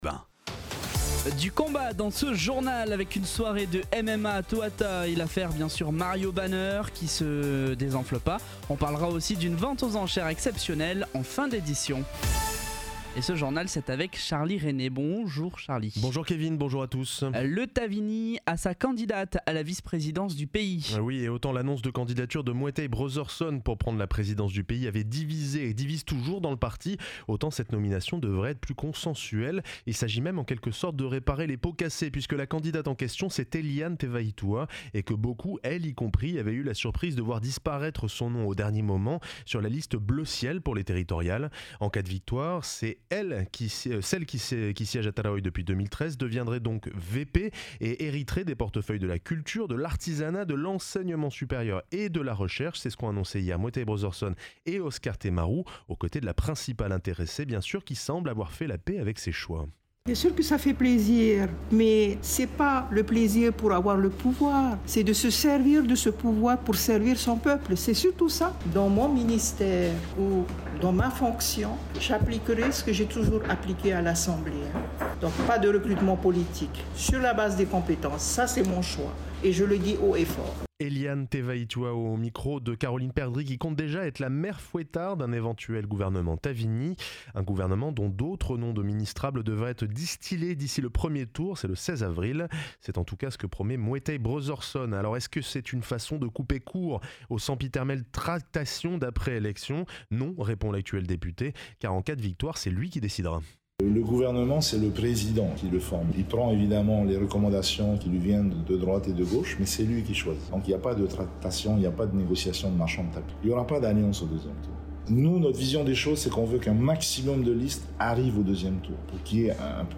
Journal de 7h, le 28/03/2023